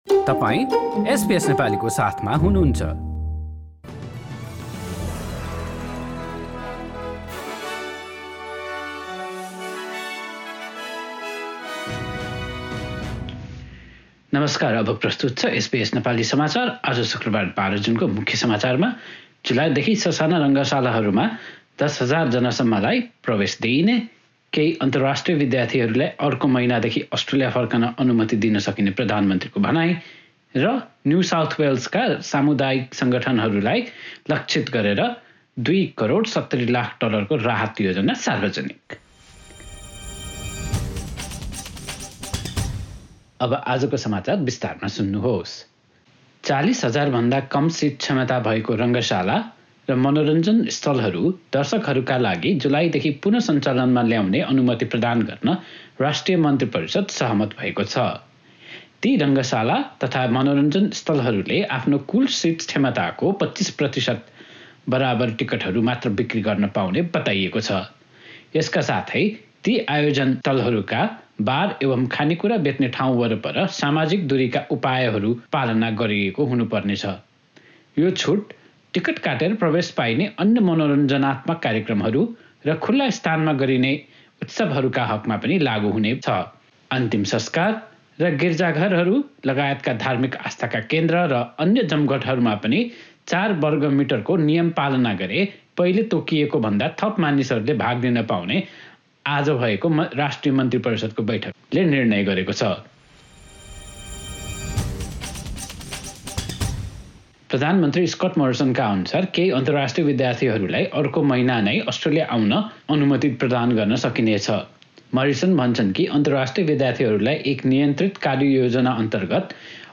Listen to the latest news headlines in Australia from SBS Nepali radio